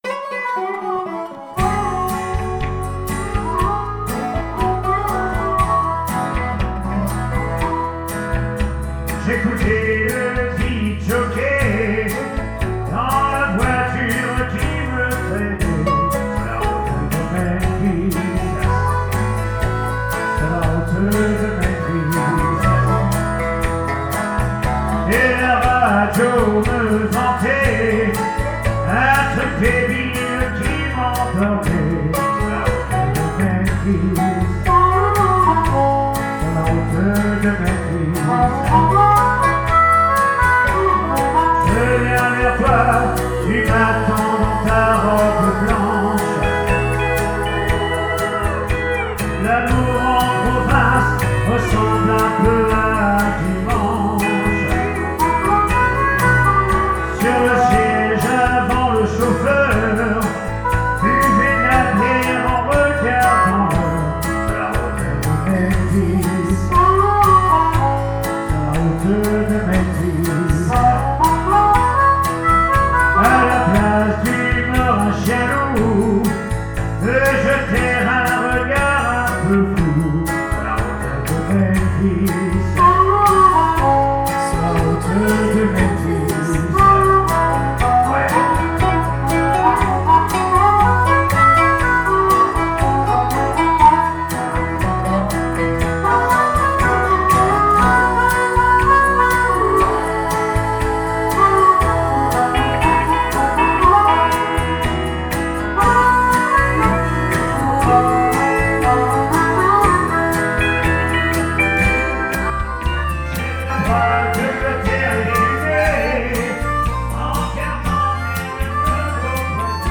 SOIREES BLUES-ROCK
DUO CHANT/HARMONICA